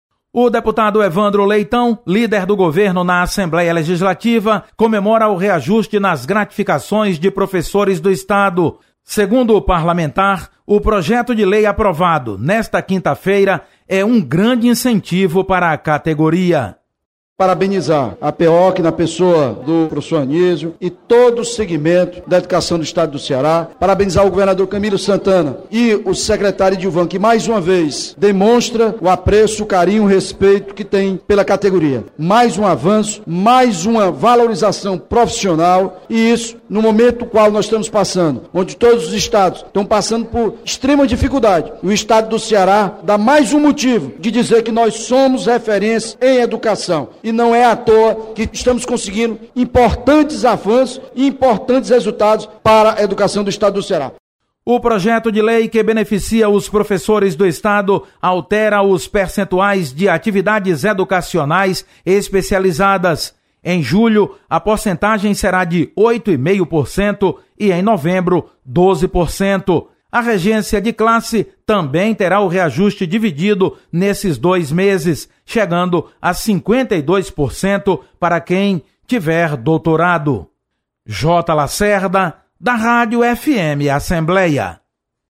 Deputado Evandro Leitão comemora reajuste nas gratificações de professores do Estado. Repórter